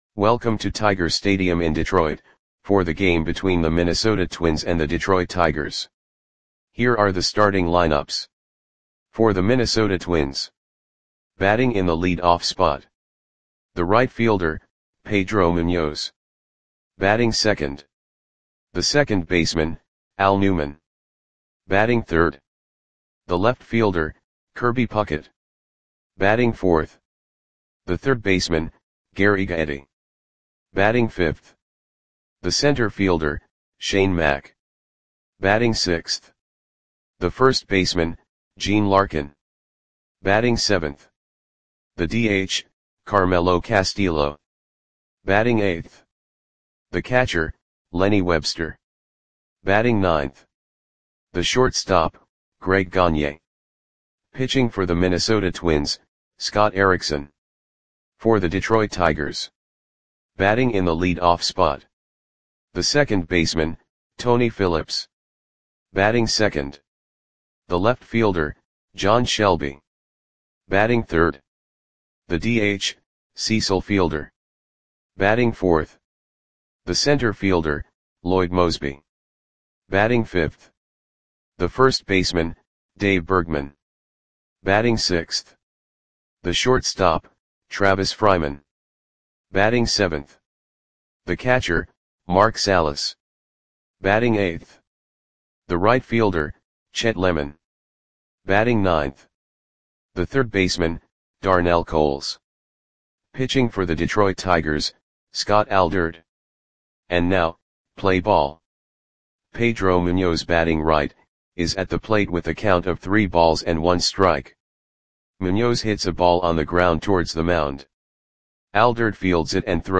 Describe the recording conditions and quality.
Twins 2 @ Tigers 0 Tiger StadiumSeptember 29, 1990 (No Comments)